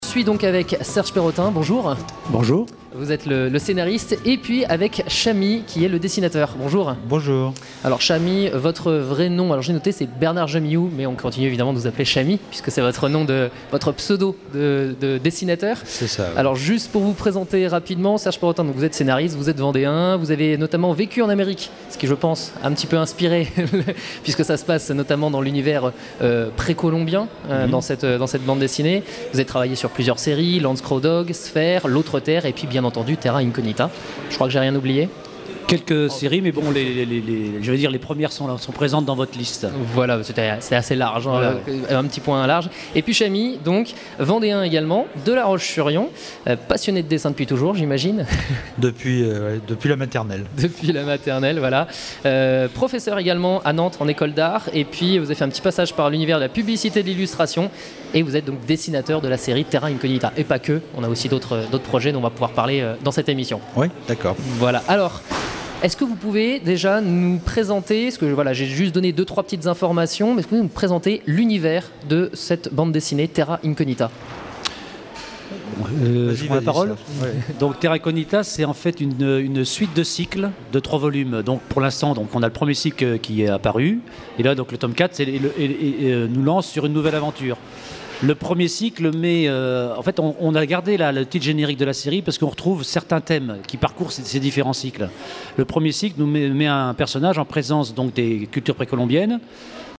Emissions de la radio RCF Vendée
Echanges avec des auteurs et dessinateurs de bandes dessinnées
Catégorie Témoignage